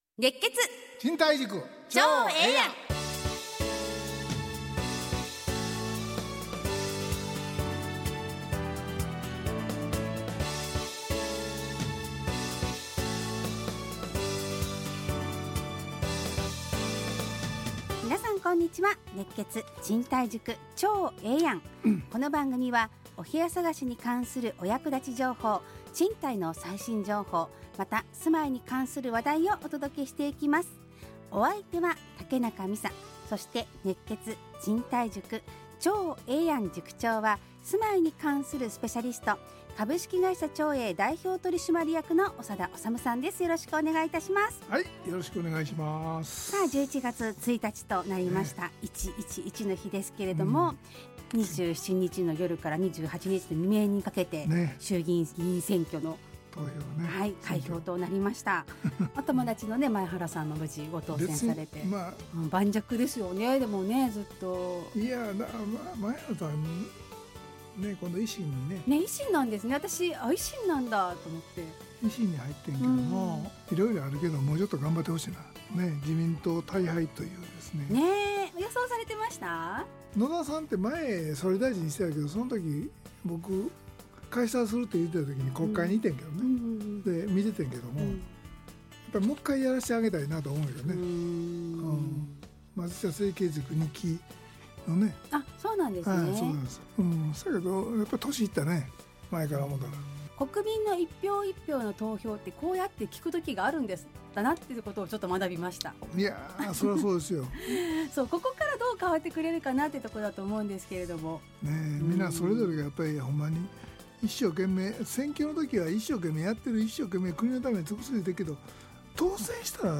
ラジオ放送 2024-11-01 熱血！